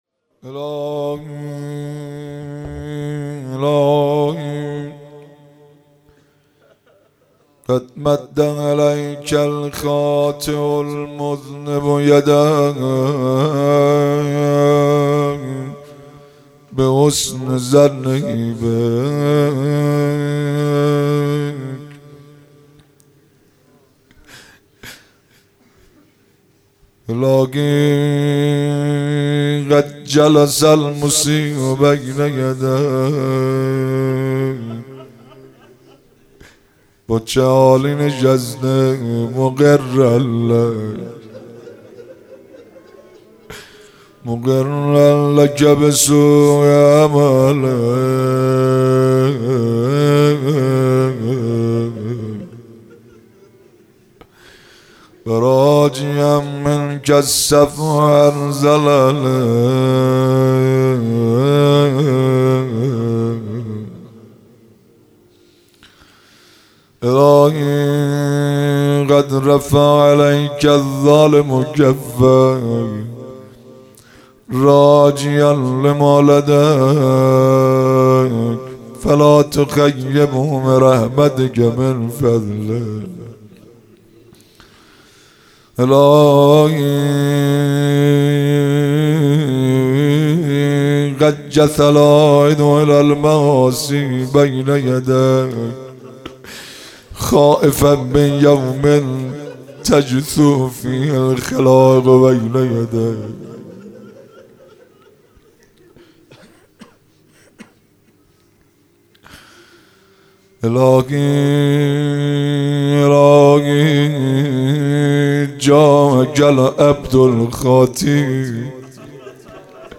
مناسبت : شب هفدهم رمضان
قالب : مناجات